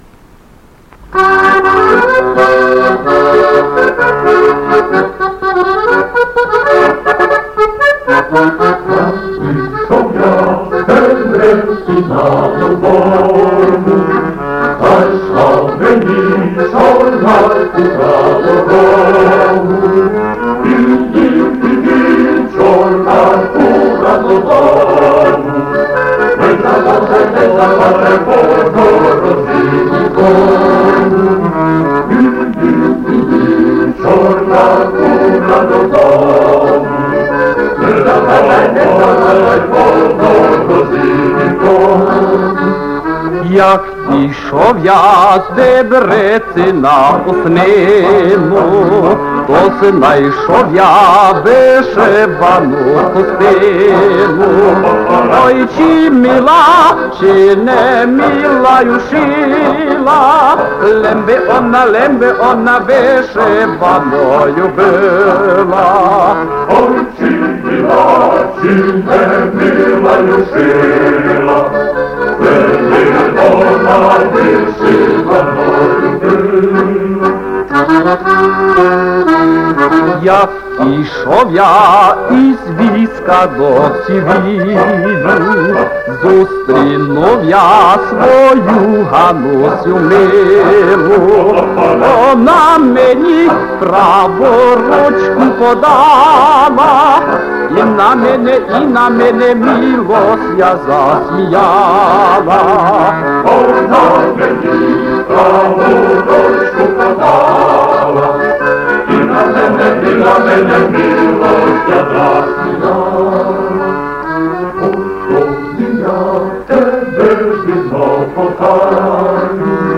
Співає хор